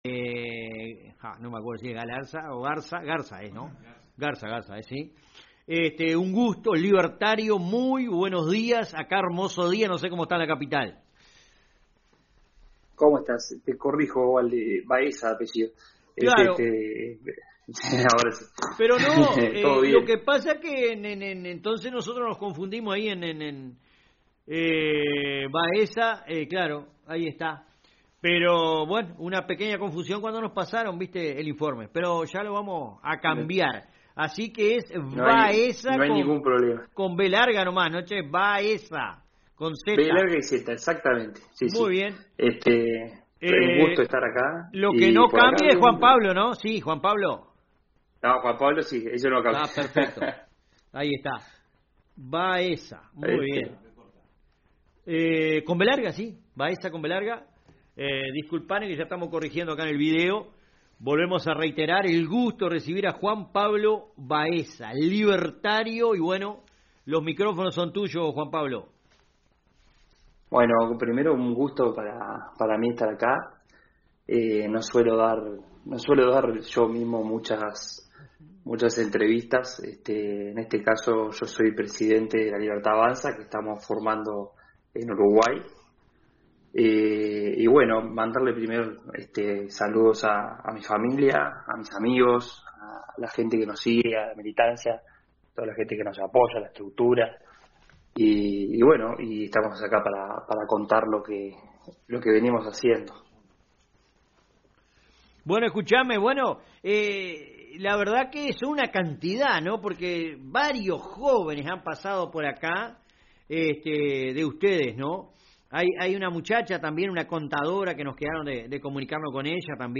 dialogamos telefónicamente